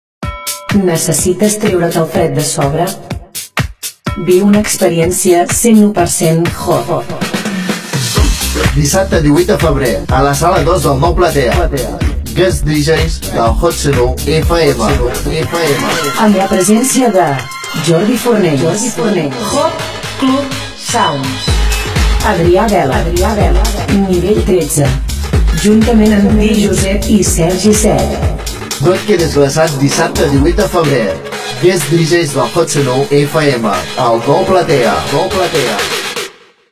24dfe1fa67e2336ce8c227a25bcad47814bc9e0c.mp3 Títol IQuart FM Emissora IQuart FM Titularitat Pública municipal Descripció Promo del Guest DJs del Hot 101 FM, al Nou Platea.